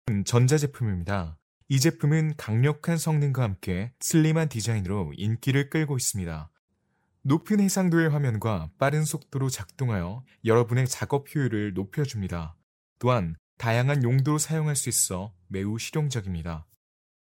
韩语翻译/韩文翻译团队成员主要由中国籍和韩国籍的中韩母语译员组成，可以提供证件类翻译（例如：驾照翻译、出生证翻译、房产证翻译，学位证翻译，毕业证翻译、成绩单翻译、无犯罪记录翻译、营业执照翻译、结婚证翻译、离婚证翻译、户口本翻译、奖状翻译、质量证书、许可证书等）、公证书翻译、病历翻译、公司章程翻译、技术文件翻译、工程文件翻译、合同翻译、审计报告翻译、视频听译/视频翻译、声音文件听译/语音文件听译等；韩语配音/韩文配音团队由韩籍韩语母语配音员组成，可以提供韩语专题配音、韩语广告配音、韩语教材配音、韩语电子读物配音、韩语产品资料配音、韩语宣传片配音、韩语彩铃配音等。
韩语样音试听下载